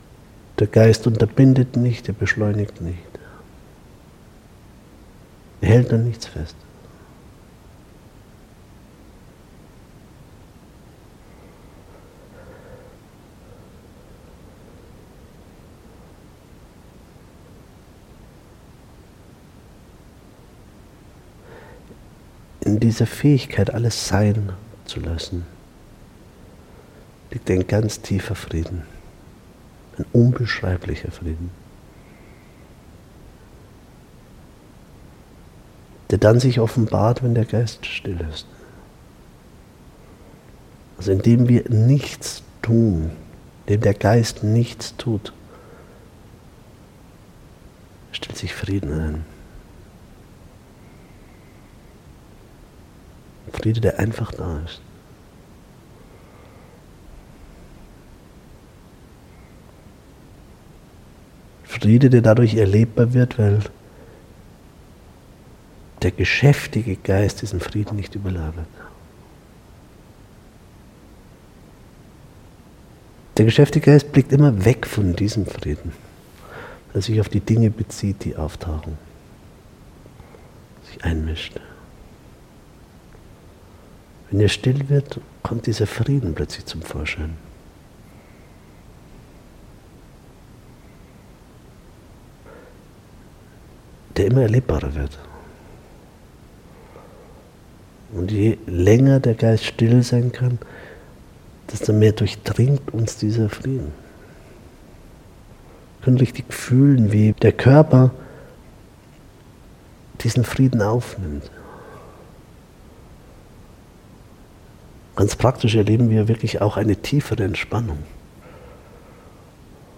Geführte Meditation ✓ Frieden durch geistige Stille erleben ✓ In und aus diesem Frieden leben ✓ Als MP3-Download ➨ Jetzt reinören!
HÖRPROBE Meditation Live-Aufnahme Kostenloser Download im MP3 Format (30 :20 Minuten), deutsch Frieden sein (MP3) Menge In den Warenkorb Kategorie: Meditation MP3s/CDs